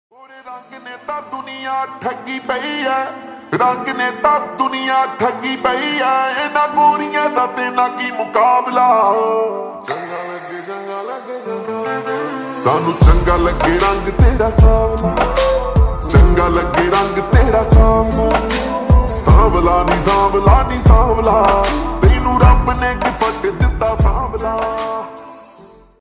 (Slowed + Reverb)
soulful vocals
duet